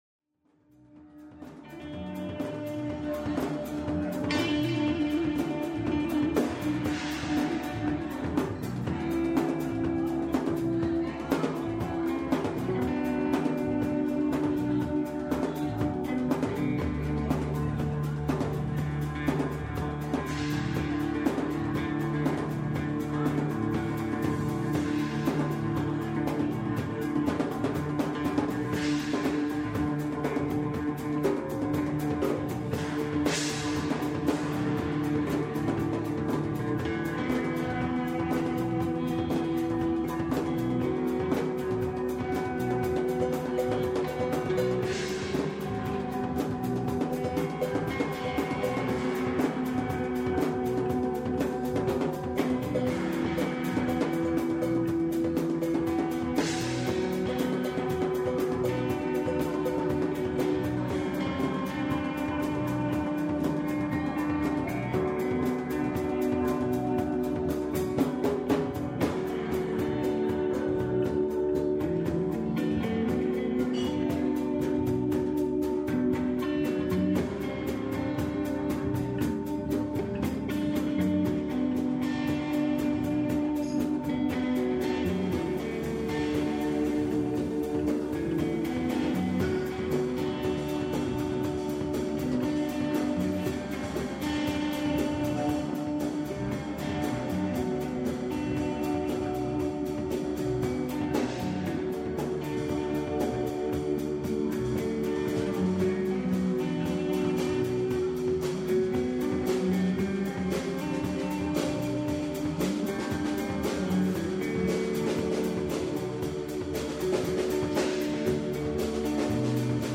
guitarist
bassist
drummer